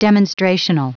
Prononciation du mot demonstrational en anglais (fichier audio)
Prononciation du mot : demonstrational